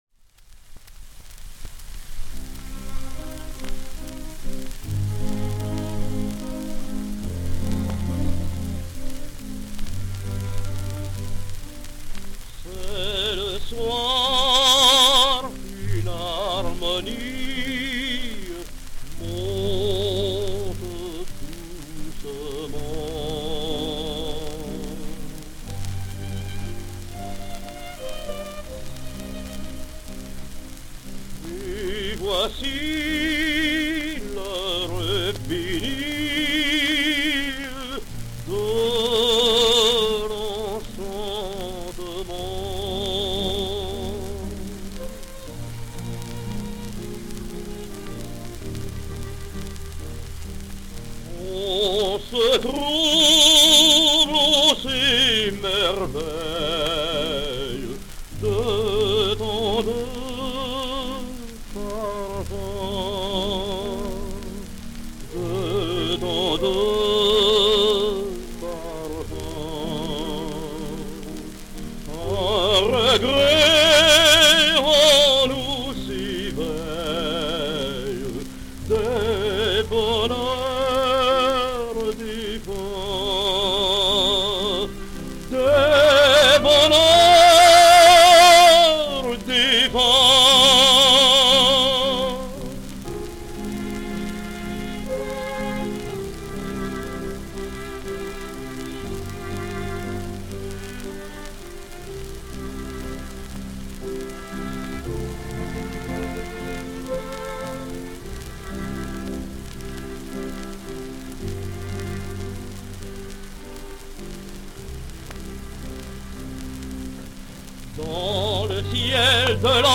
He must certainly have retired when leaving the Opéra-Comique; his electrical recordings prove that his voice was a wreck at that time.
Consortium, Paris, 1933 or 1934